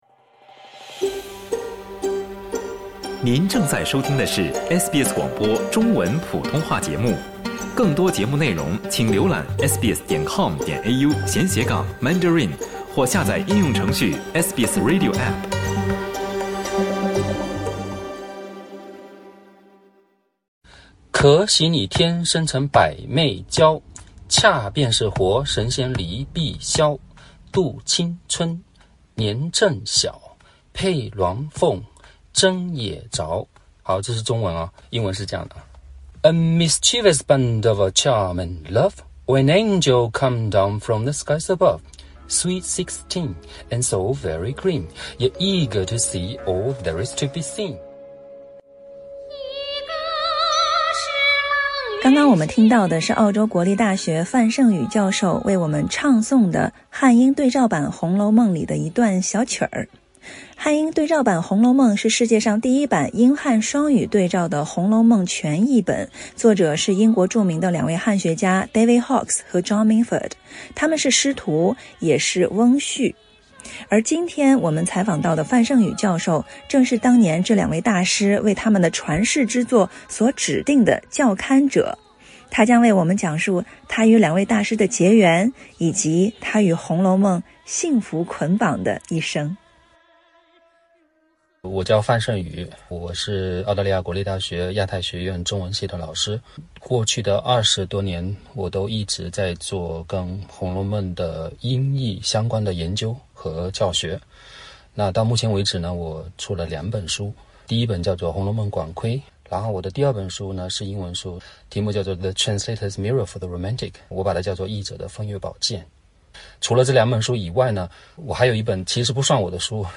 采访中